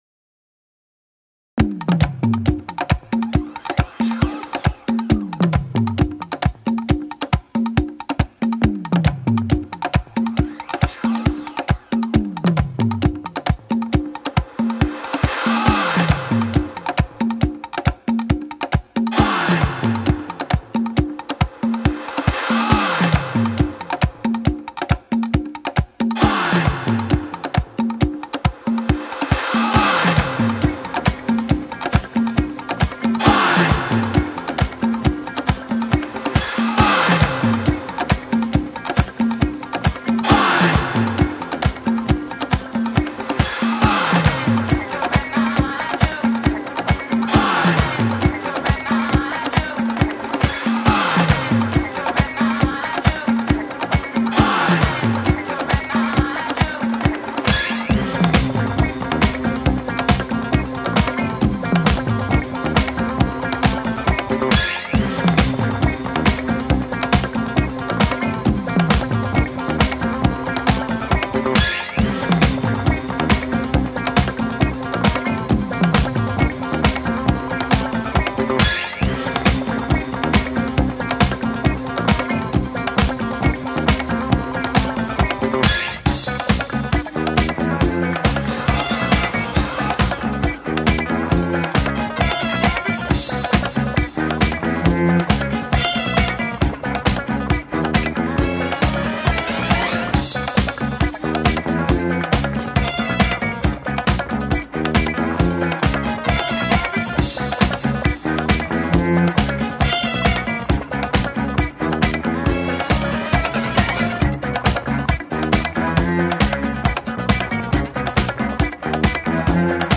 disco project